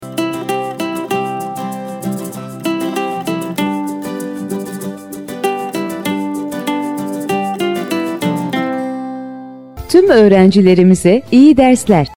Okulumuz Öğrenci Ders Giriş Zili